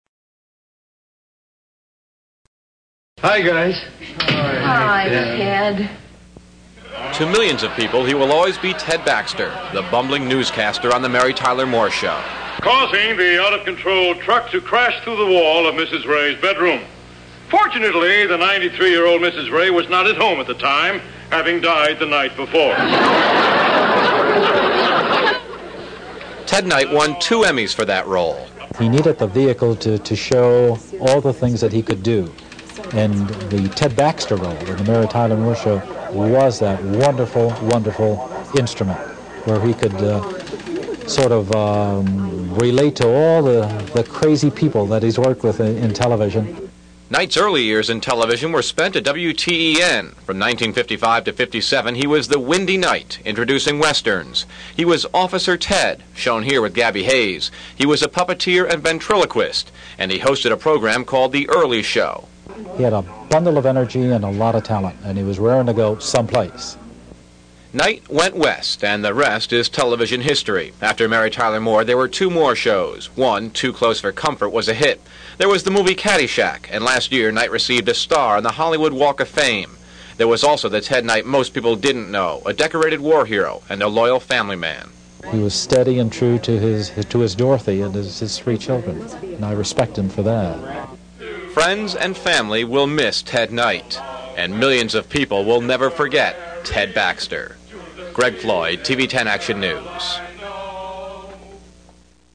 Here is what I found in the WTEN archives under “Knight, Ted Obituary” which ran in August 1986 at the time of his death.